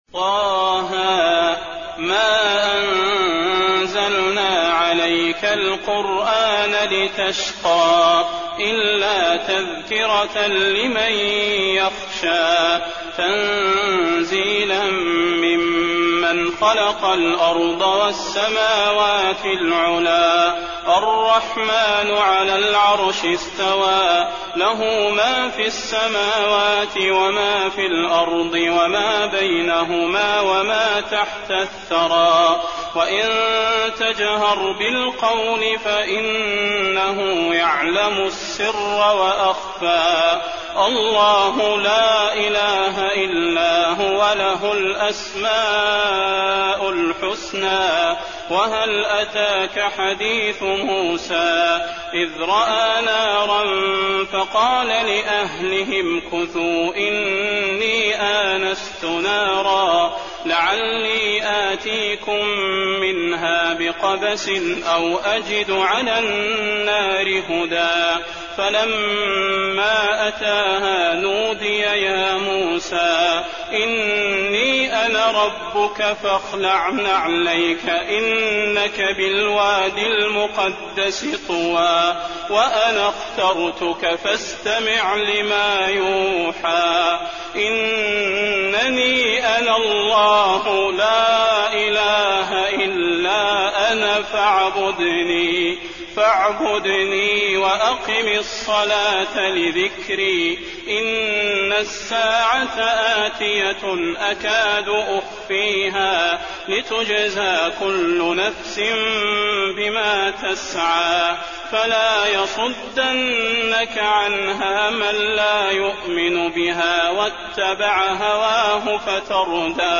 تراويح الليلة الخامسة عشر رمضان 1422هـ سورة طه كاملة Taraweeh 15 st night Ramadan 1422H from Surah Taa-Haa > تراويح الحرم النبوي عام 1422 🕌 > التراويح - تلاوات الحرمين